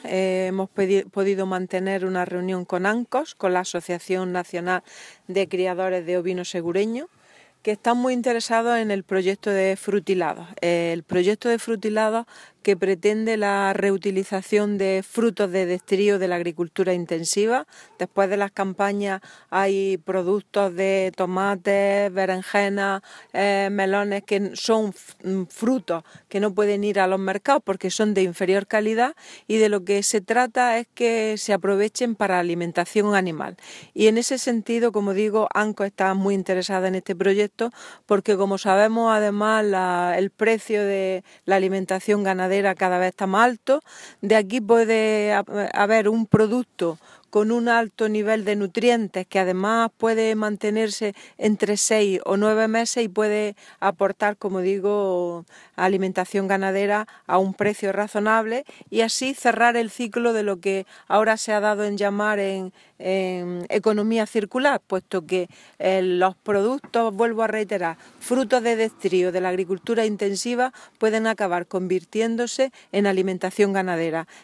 Declaraciones de Carmen Ortiz sobre Ancos y Frutilados.